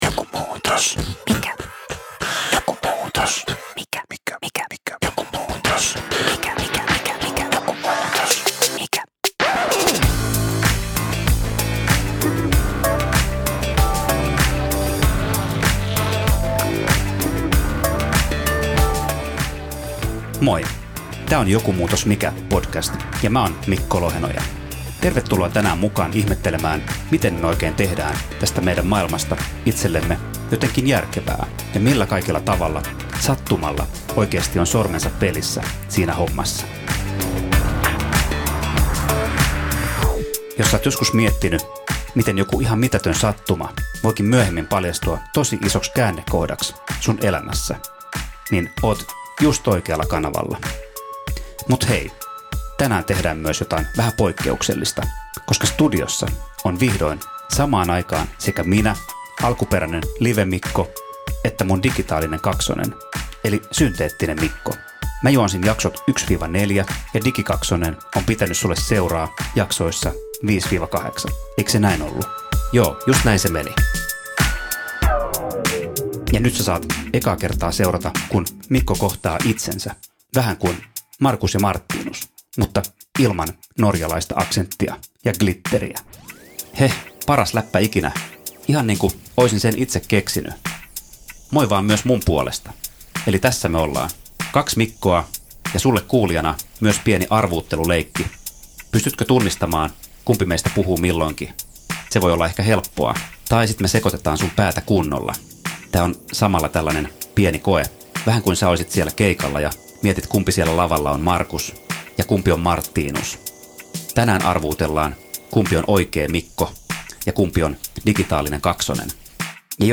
alkuperäinen juontaja ja hänen sattuman kautta syntynyt digitaalinen kaksonen. Sukellamme arjen sattumien, merkitysten rakentamisen ja digijuontajan synnyn hetkiin.